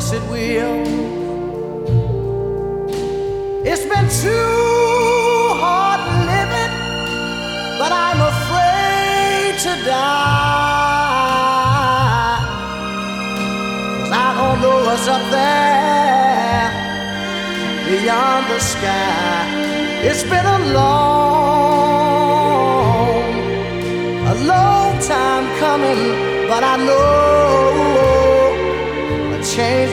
Genre: R&B/Soul